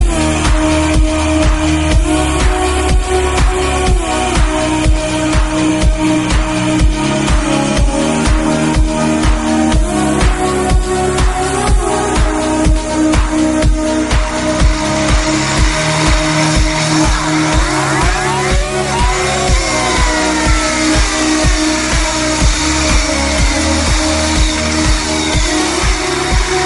Kategorien Elektronische